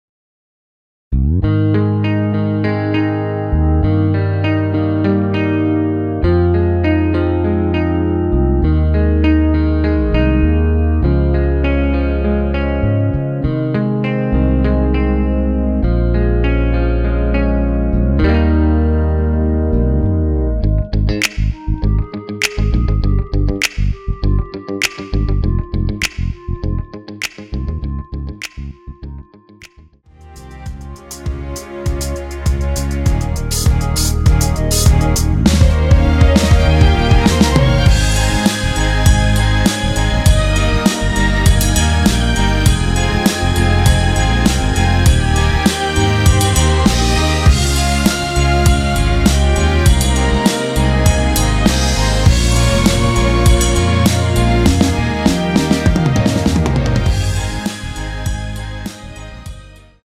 원키에서(-1)내린 멜로디 포함된 MR입니다.(미리듣기 확인)
Ab
앞부분30초, 뒷부분30초씩 편집해서 올려 드리고 있습니다.
중간에 음이 끈어지고 다시 나오는 이유는